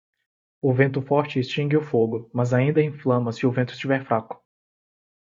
Pronounced as (IPA)
/ˈfɾa.ku/